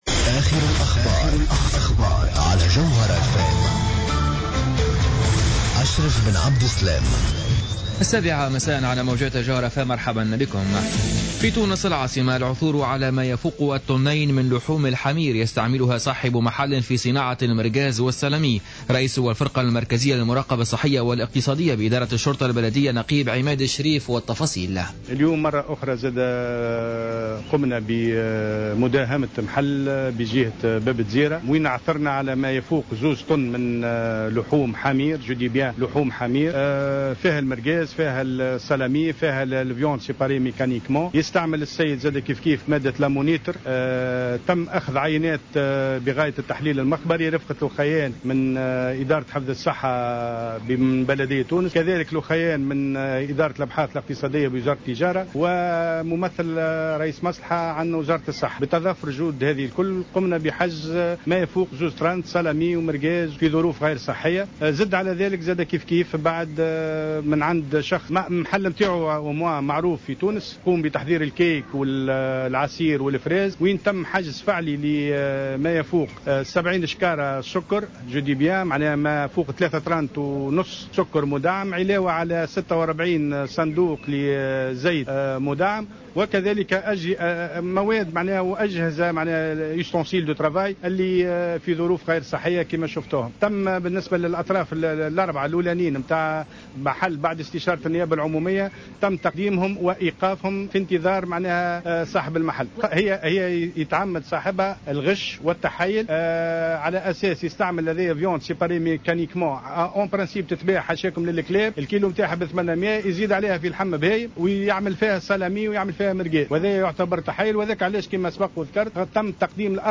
نشرة أخبار السابعة مساء ليوم الخميس 7 ماي 2015